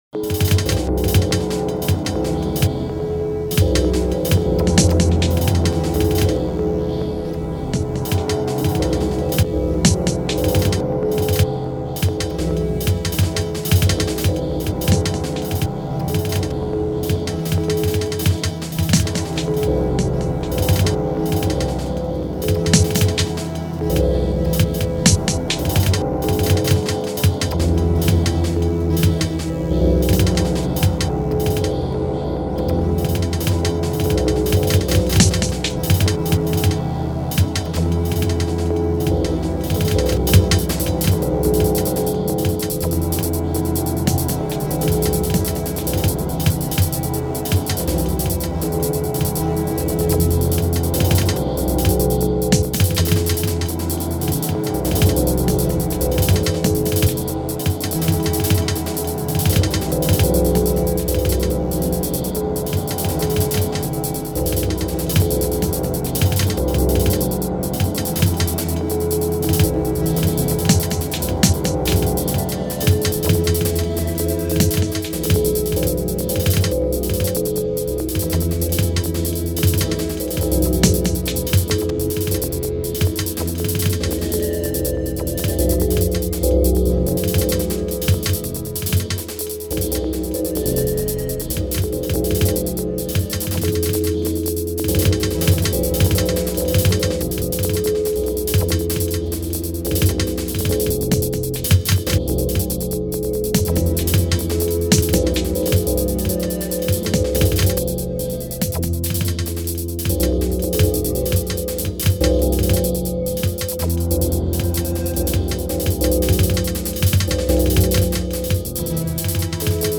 But it s not loud or trying to show off.